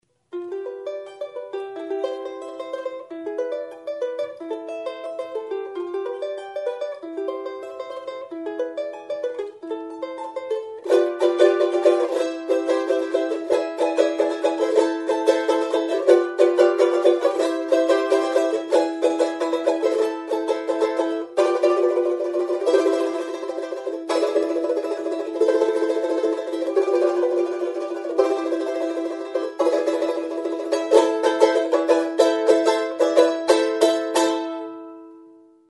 Cordes -> Pincées
Enregistré avec cet instrument de musique.
CHARANGO
Erresonantzia kaxa osatzeko 'armadillo' animaliaren oskolarekin egina dago. 5 soka bikoitz ditu.